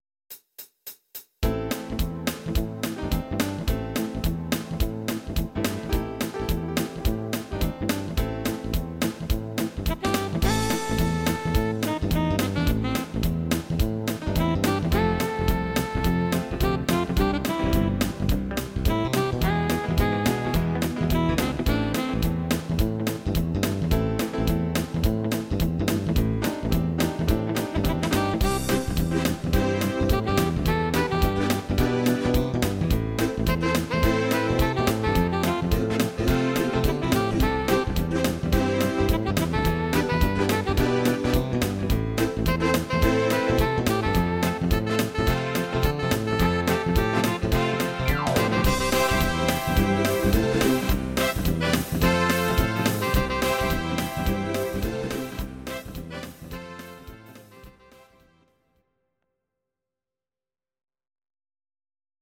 Audio Recordings based on Midi-files
Pop, Musical/Film/TV, 1970s